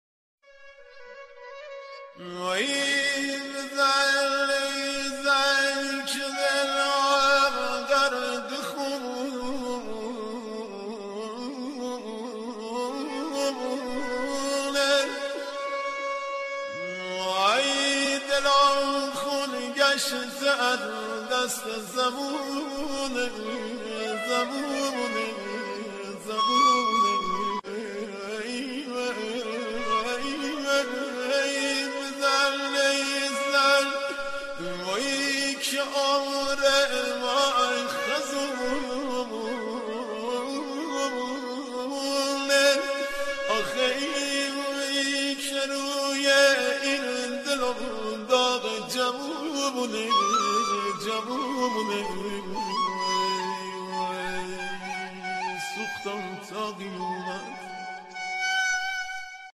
آهنگ سوزناک دشتی